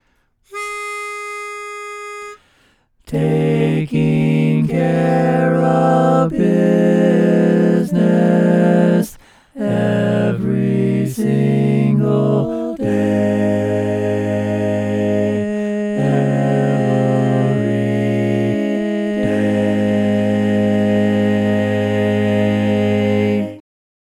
Key written in: A♭ Major
How many parts: 4
Type: Barbershop
All Parts mix:
Learning tracks sung by